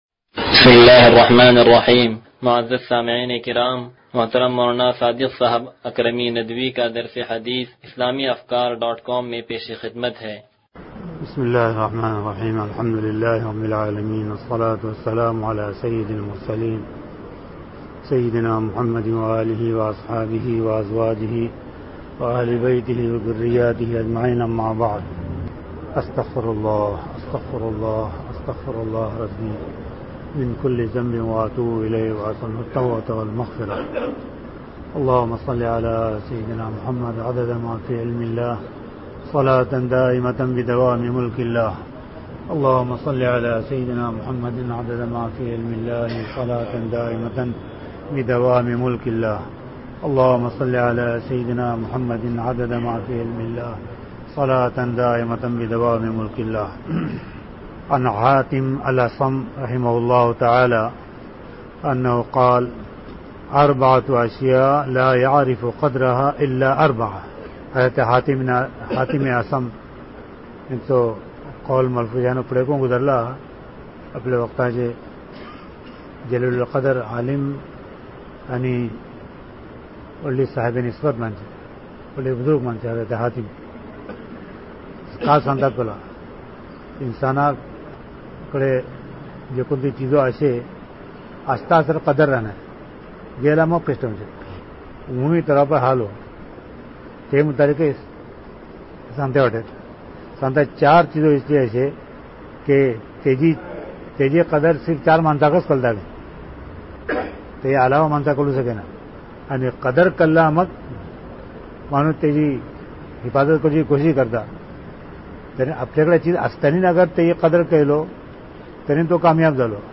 درس حدیث نمبر 0092